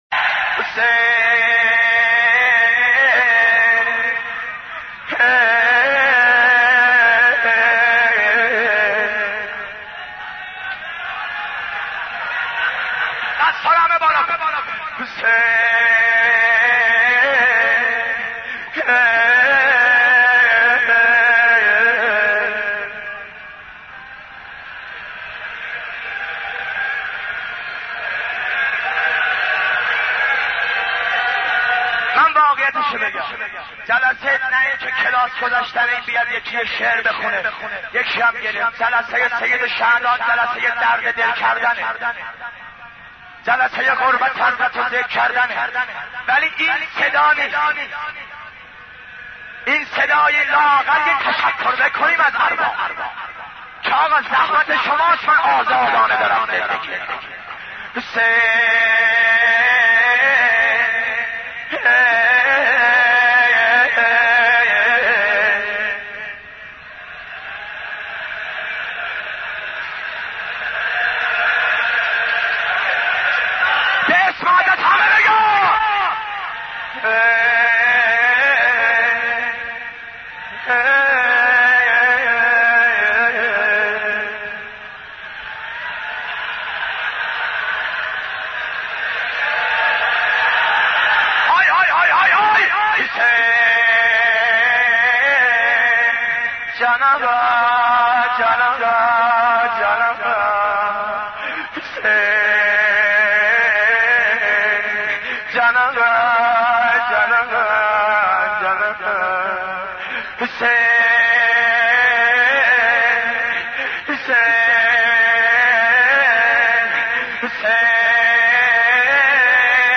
امام حسین ـ شور 27